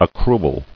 [ac·cru·al]